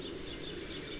CommonStarling_102761_45.wav